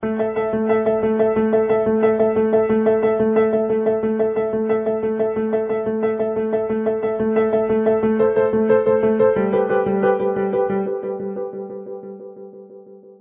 Grand Piano Loop
Royalty free energetic piano loop.
32kbps-Grand-Piano-loop.mp3